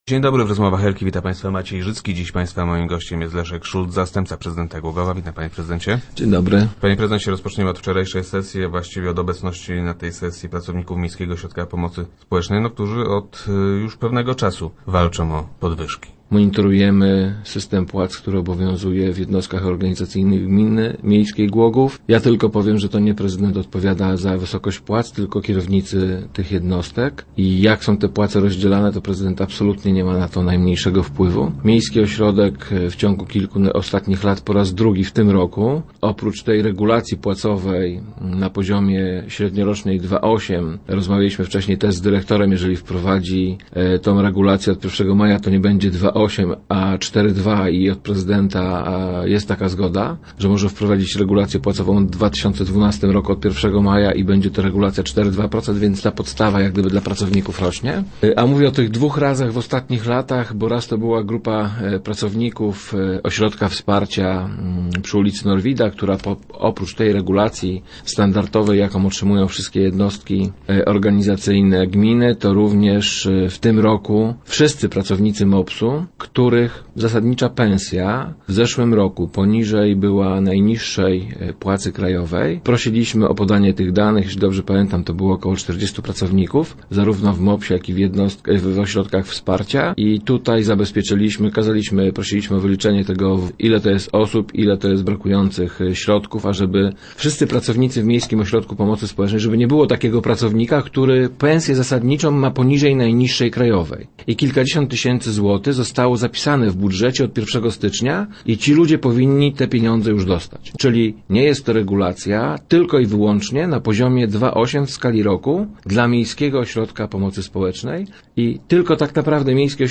- Wiadomo już jednak, że  zrealizowane naszych zasobach inwestycje sprawdzają się - mówi Leszek Szulc, zastępca prezydenta, który był gościem Rozmów Elki.